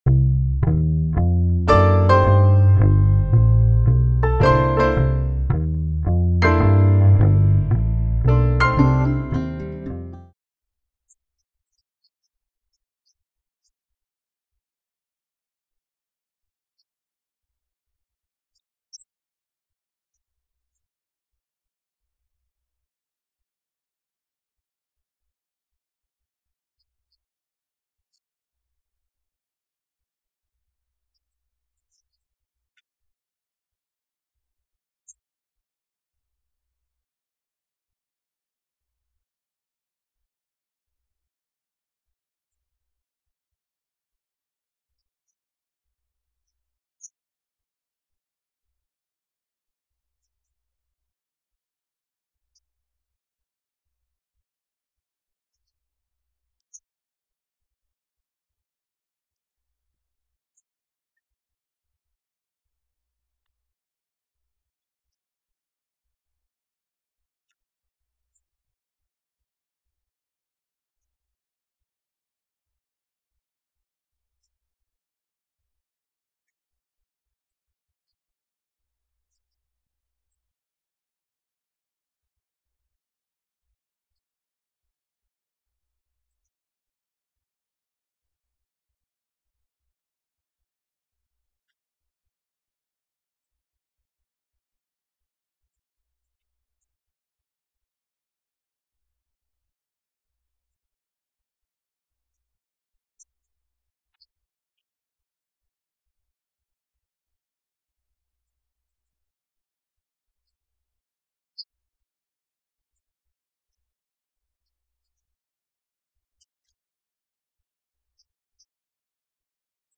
Single Series Sermon Passage: Matthew 2:1-12 Service Type: Sunday Worship « Christmas Eve